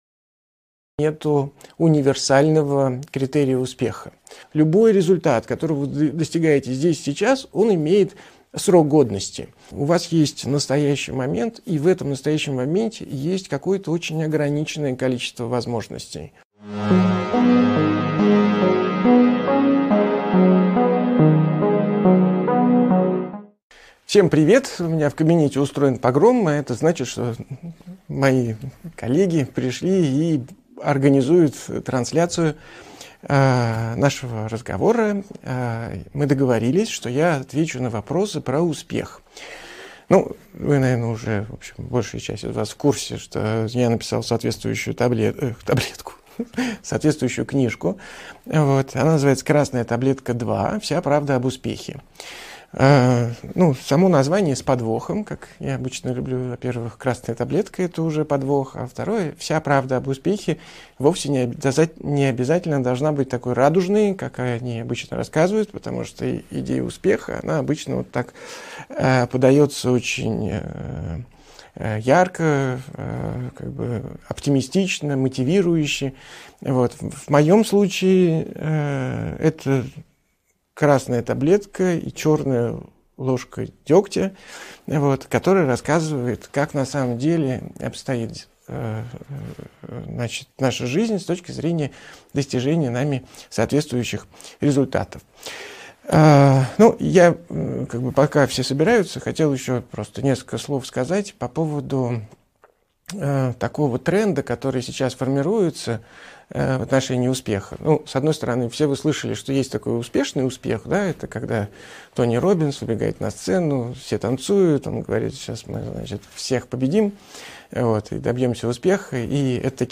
Автор Андрей Курпатов Читает аудиокнигу Андрей Курпатов.